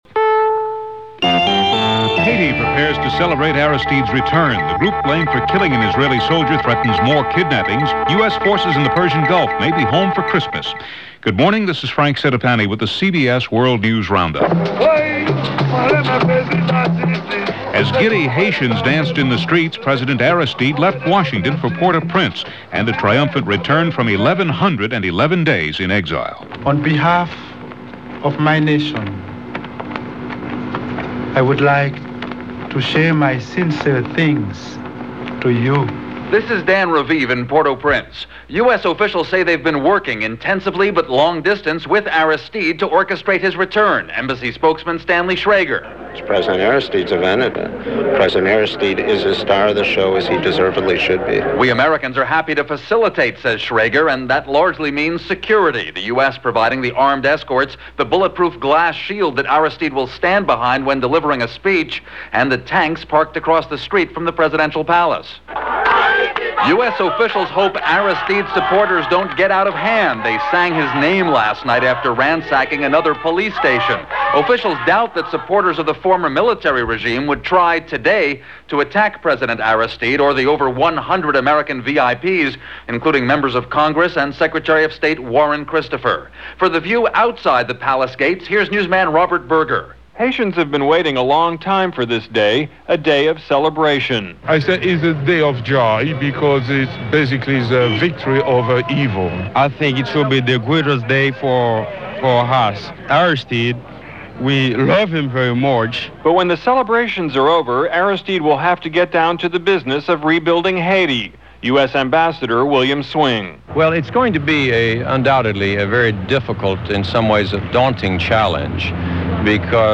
– CBS World News Roundup – October 15, 1994 – Gordon Skene Sound Collection –